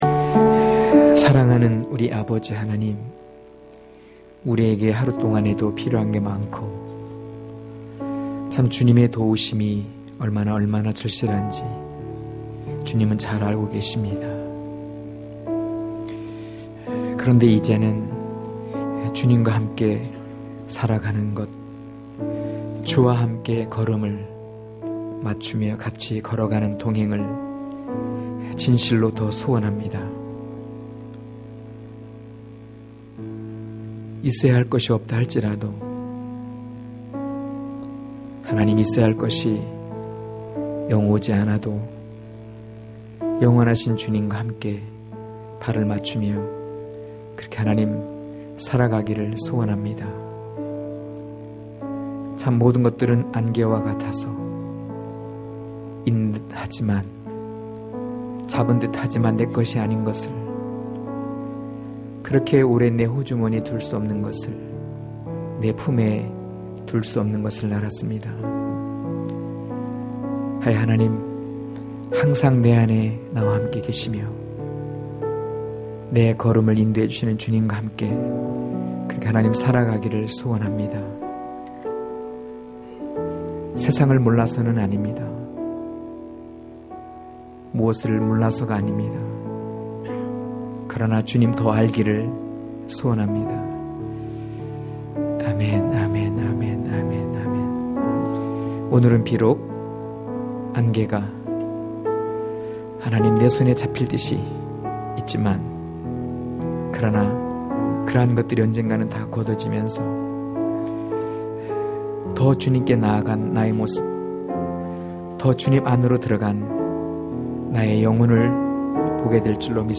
강해설교 - 3.천국백성(요일2장1-6절)